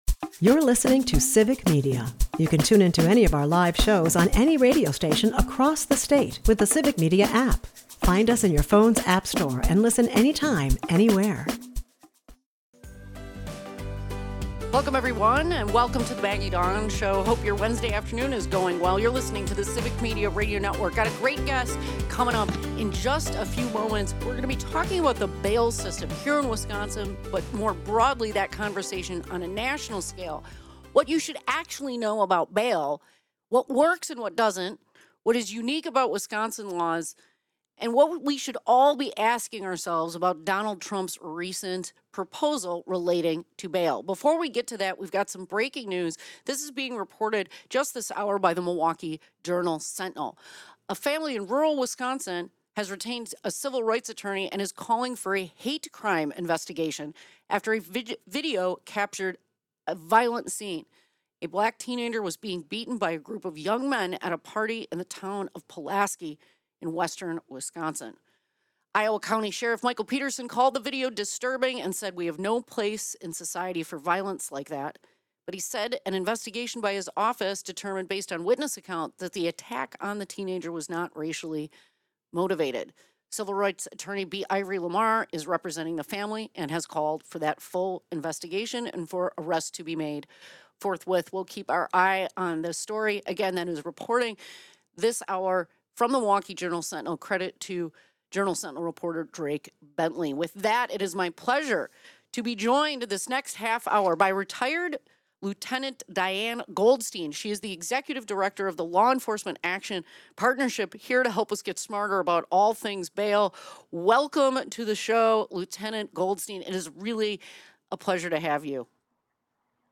As civil rights concerns flare over a violent incident in Wisconsin, the conversation turns to Trump’s controversial invocation of the Alien Enemies Act. The ultra-conservative Fifth Circuit Court pushes back on his claims, underscoring the importance of judicial review.